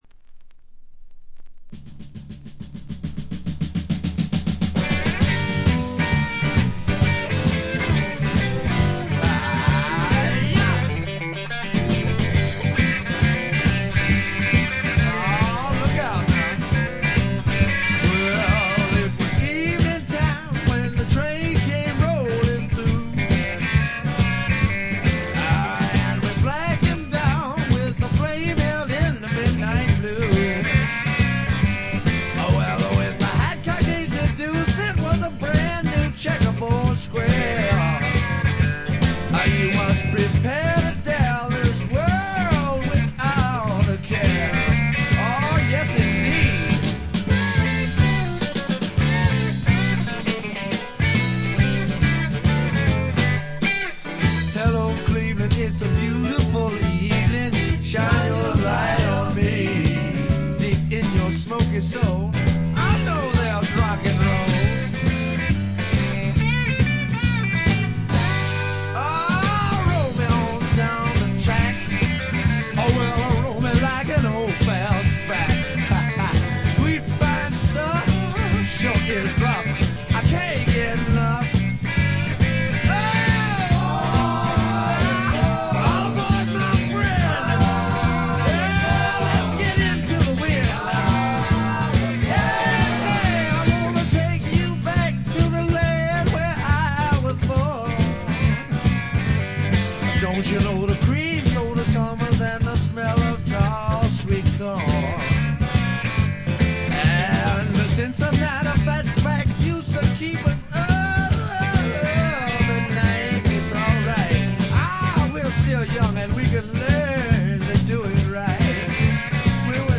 部分試聴ですが、ごくわずかなノイズ感のみ。
これはもう最高のR&B／ロックンロールと言うしかないでしょう。
試聴曲は現品からの取り込み音源です。